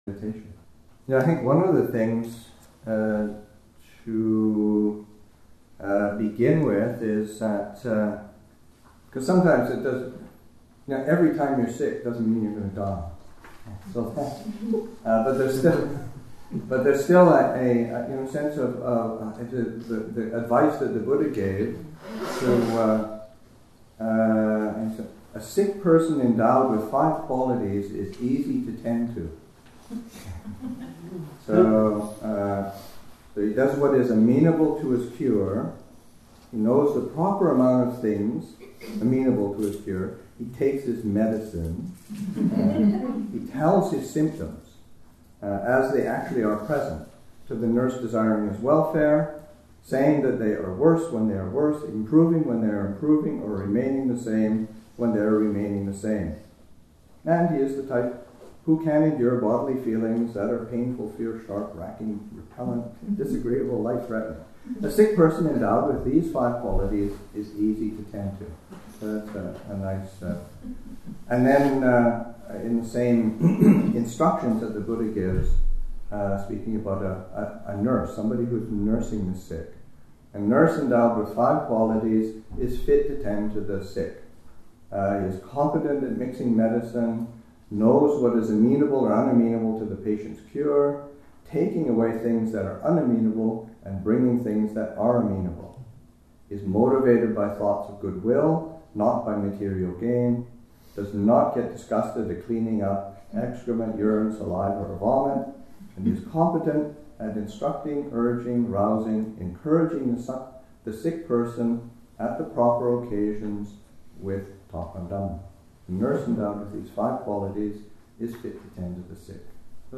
Abhayagiri Buddhist Monastery in Redwood Valley, California
2. [5:17] Reading: Five qualities of good patients and nurses (Mahāvagga 8.26.5) [Sickness] [Health care ]